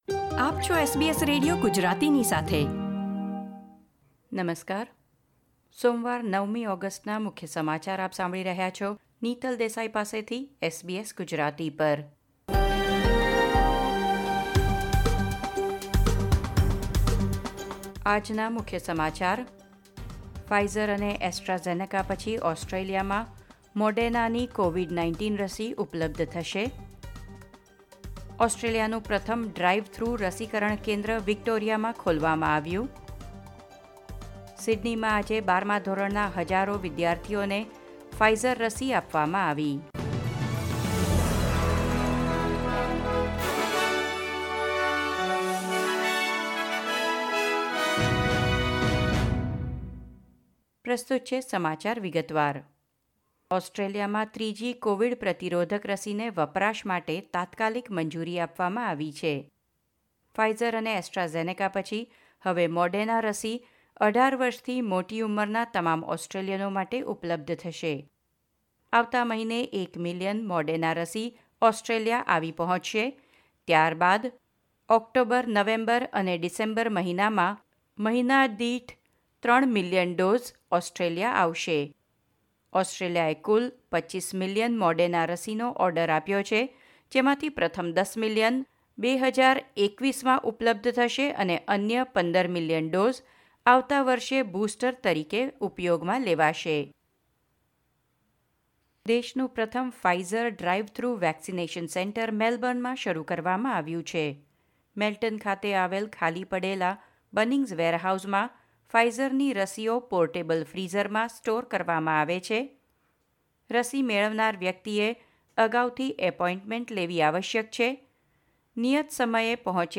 SBS Gujarati News Bulletin 9 August 2021